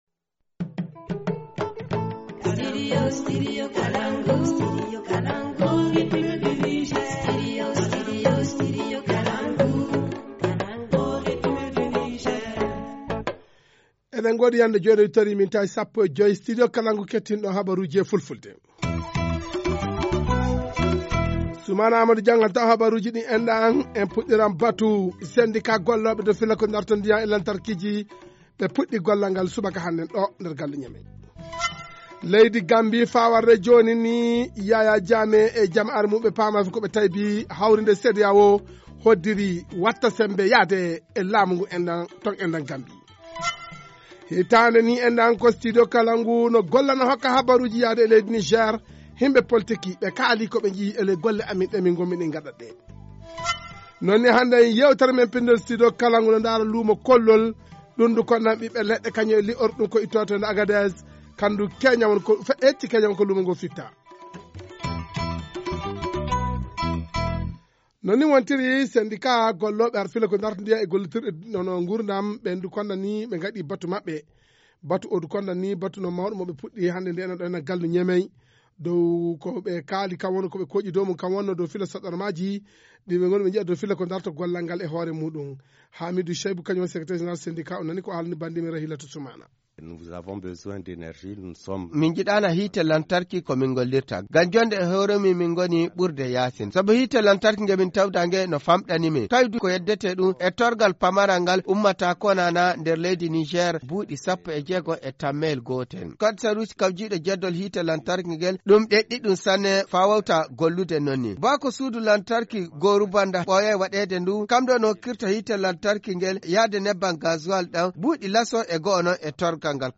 2. Une éventuelle intervention militaire de la CEDEAO dans la crise en Gambie. A suivre l’analyse d’un historien dans ce journal.